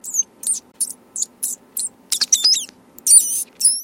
На этой странице собраны звуки, которые издают крысы: от тихого писка до активного шуршания.
Звук пищащей крысы